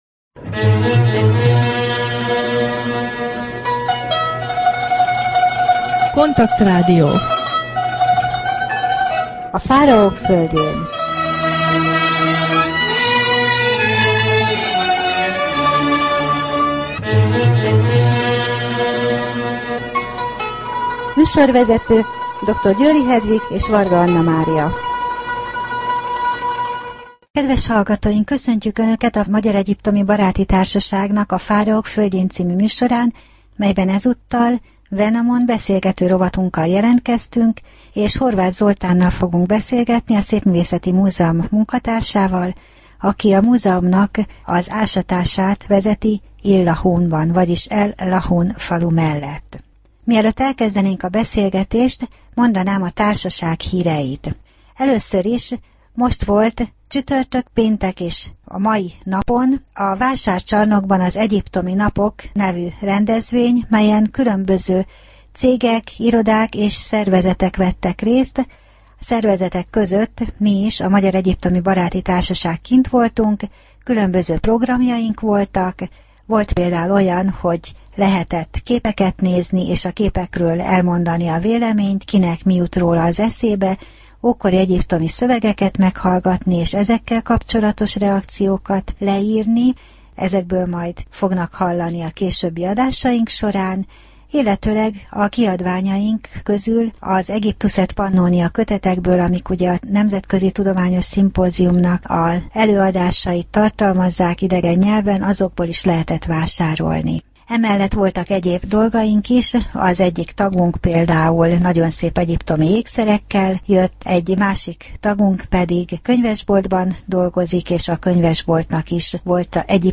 Wenamon beszélgető rovat / Az illahuni ásatások | Magyar Egyiptomi Baráti Társaság